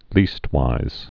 (lēstwīz)